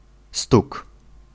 Ääntäminen
Synonyymit fesse foufoune pailler carassin commun Ääntäminen France: IPA: /møl/ Tuntematon aksentti: IPA: /mœl/ Haettu sana löytyi näillä lähdekielillä: ranska Käännös Ääninäyte Substantiivit 1. stóg {m} Suku: f .